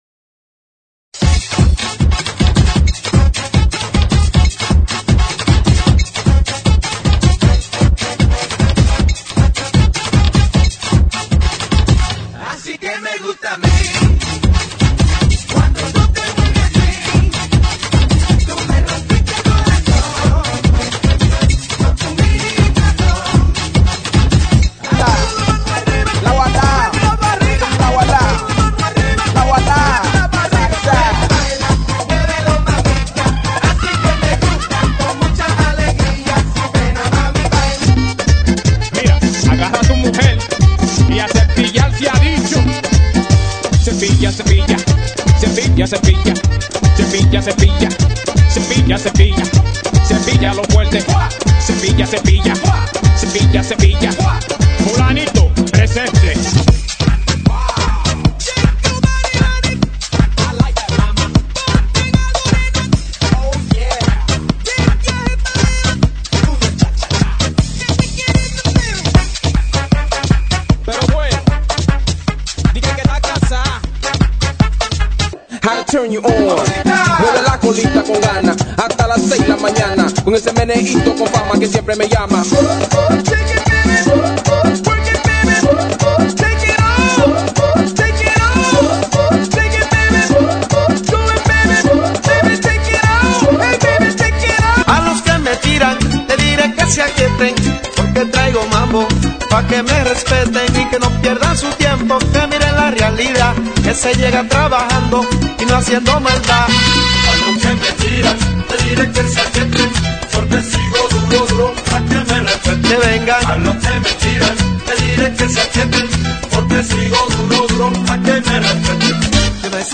MERENGUE